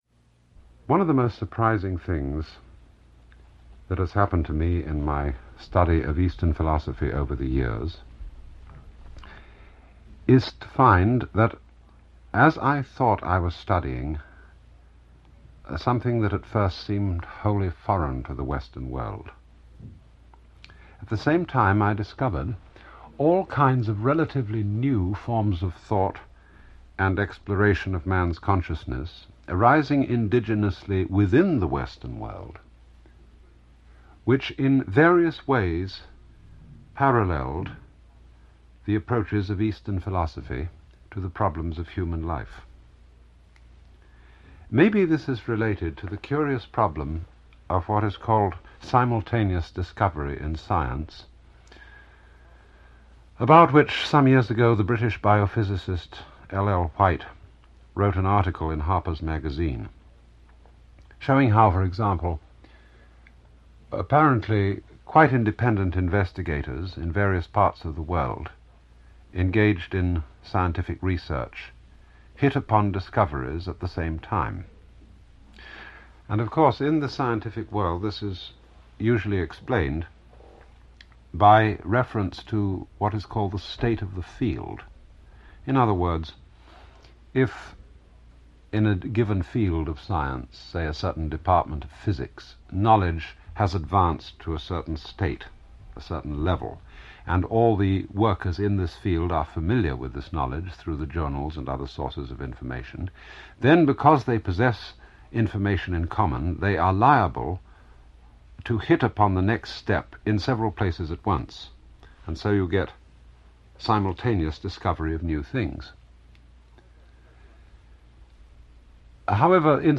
Alan Watts – Early Radio Talks – 15 – Parallel Thinking – Sleepy Tooth Dental
Alan-Watts-Early-Radio-Talks-15-Parallel-Thinking.mp3